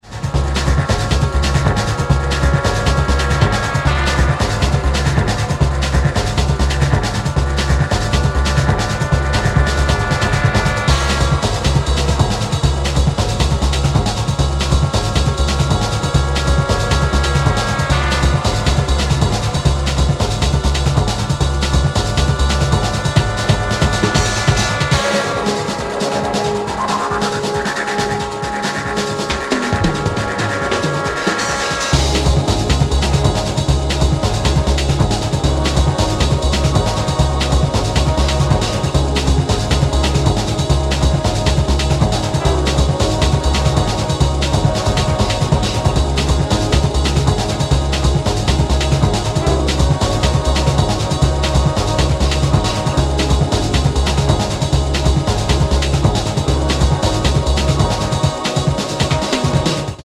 Still phat as ****new jazz latin 12 from 2001.